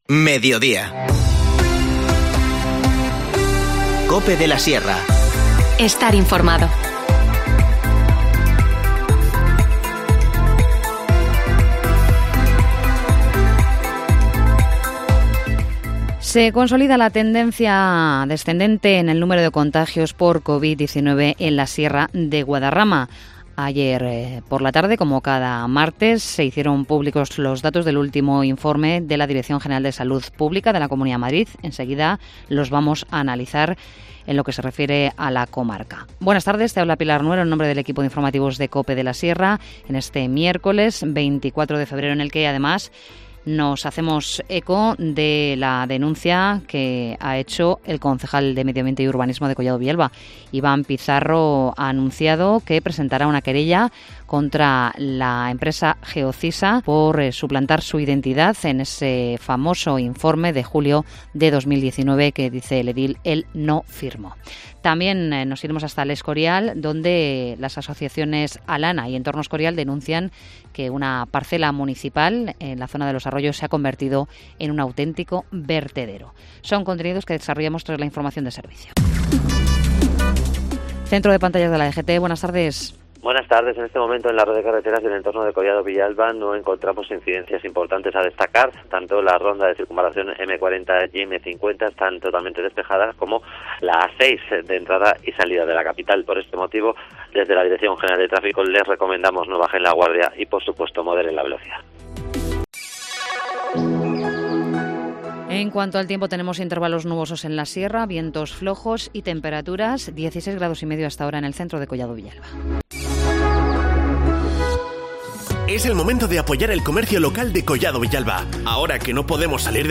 Informativo Mediodía 24 febrero